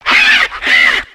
MANDIBUZZ.ogg